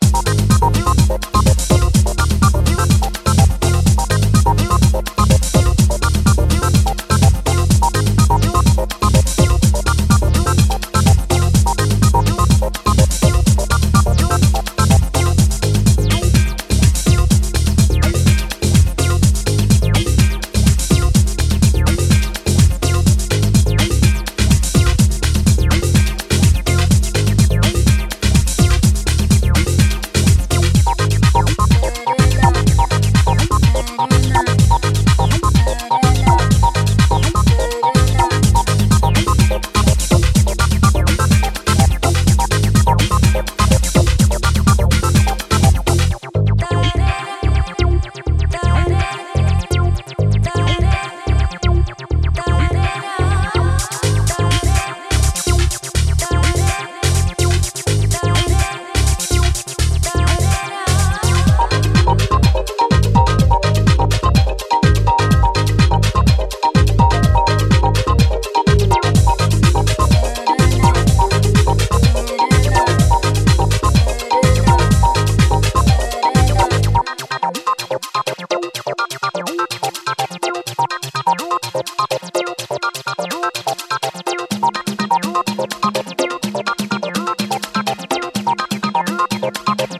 ジャンル(スタイル) AMBIENT / TECHNO / HOUSE